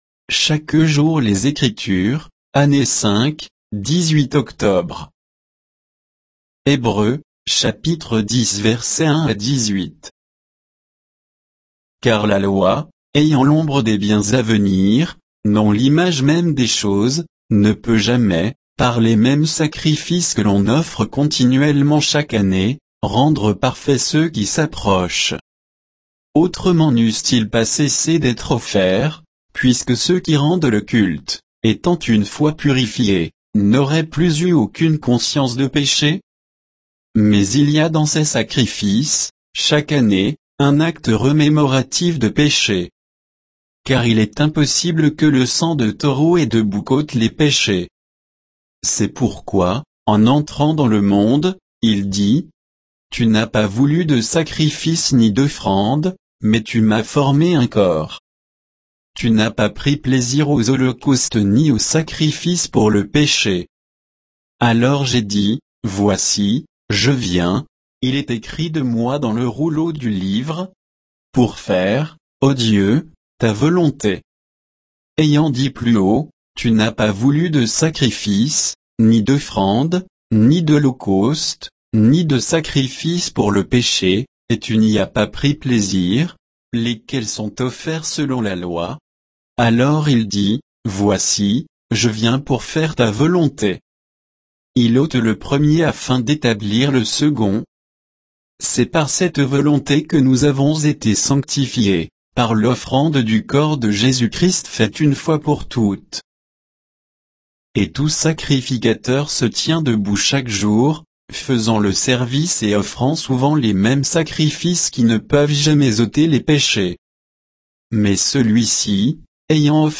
Méditation quoditienne de Chaque jour les Écritures sur Hébreux 10, 1 à 18